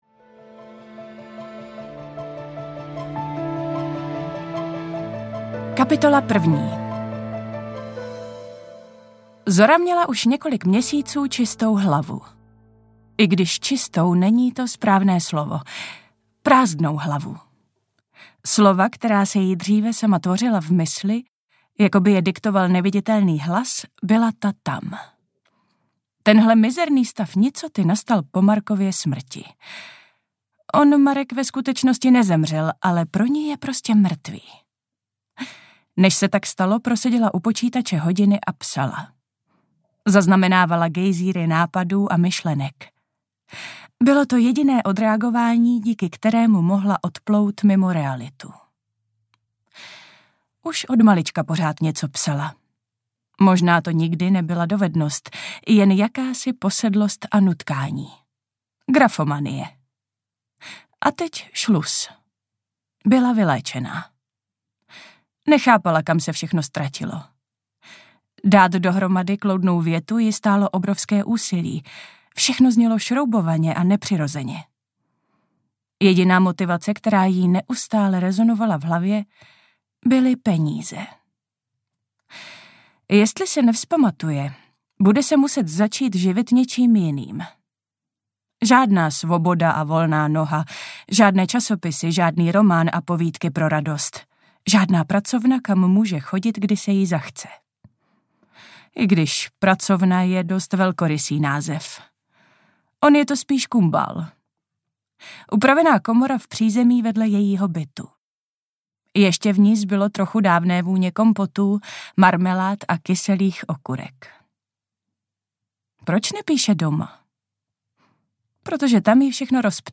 Panáček z popela audiokniha
Ukázka z knihy
• InterpretAnna Fixová